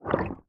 Minecraft Version Minecraft Version snapshot Latest Release | Latest Snapshot snapshot / assets / minecraft / sounds / mob / squid / ambient4.ogg Compare With Compare With Latest Release | Latest Snapshot